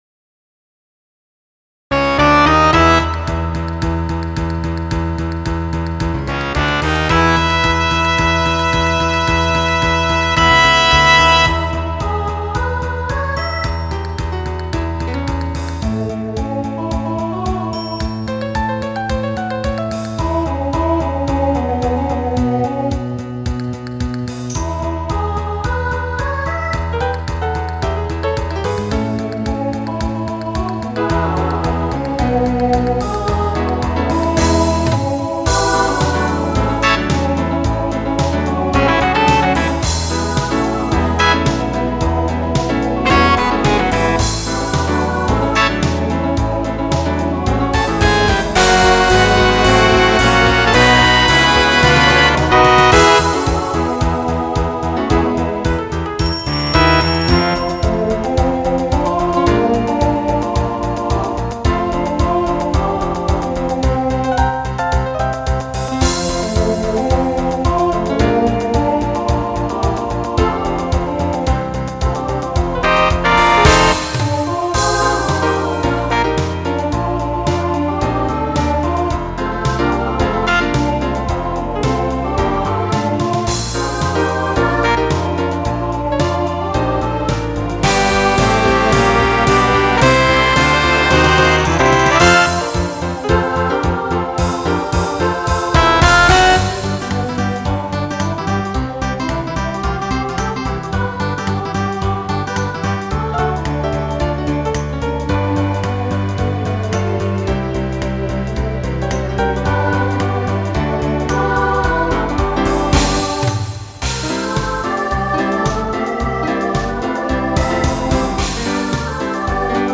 New SSA voicing for 2022.